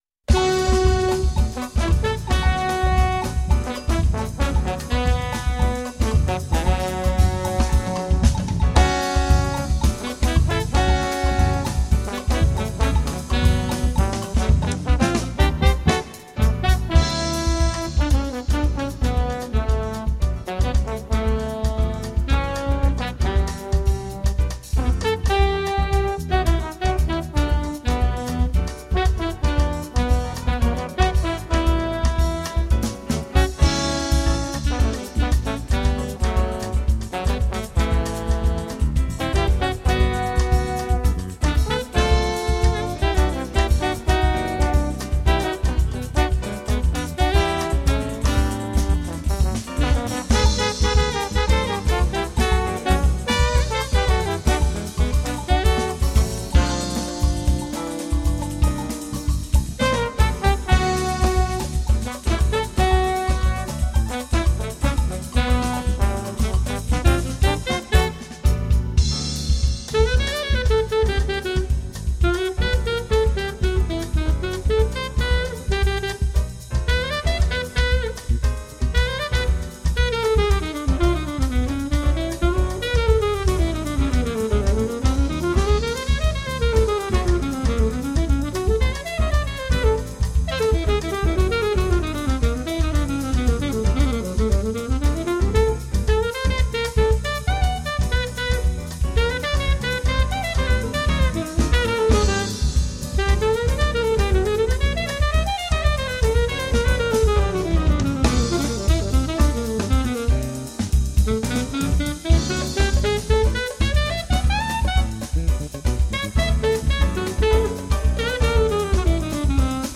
Jazz, funk and rock with a brazilian flair.